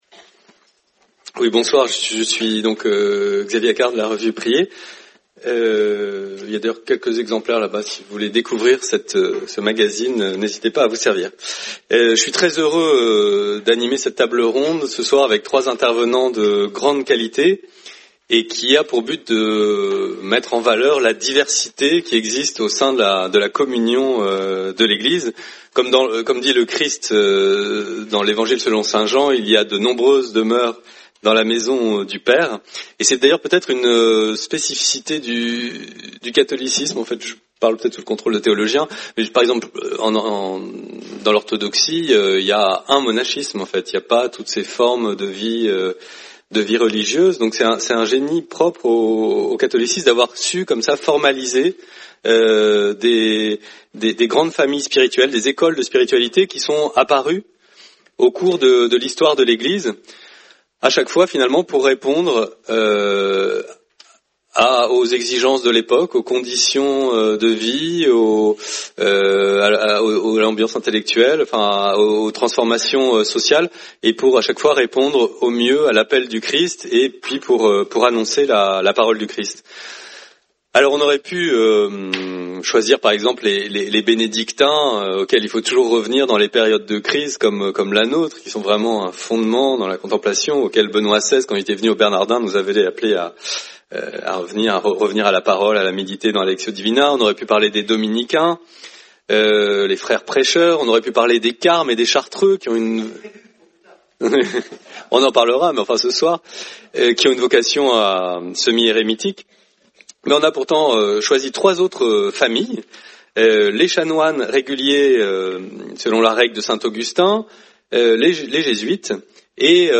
Soirée du 24 septembre 2019 autour de trois livres sur les spiritualités franciscaine, augustinienne et jésuite. Les présentations ainsi rapprochées de ces grandes tradition spirituelles permettent de mieux en montrer l’originalité et la force.